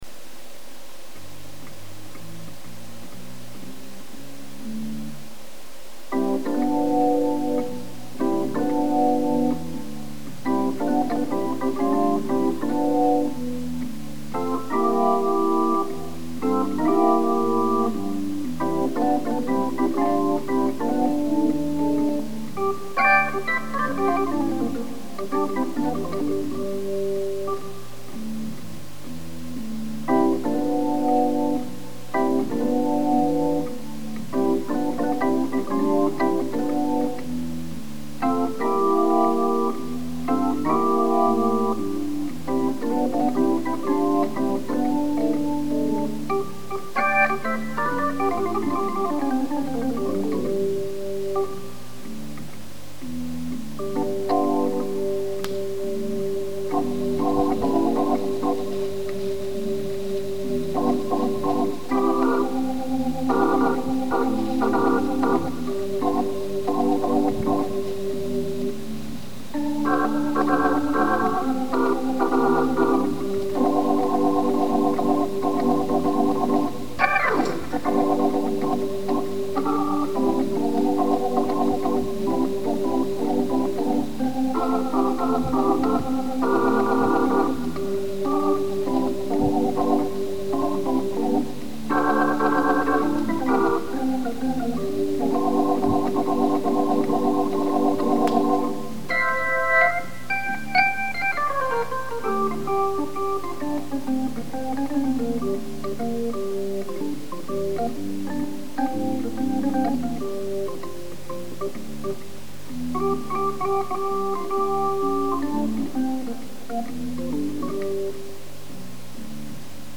lab Hammond XB 1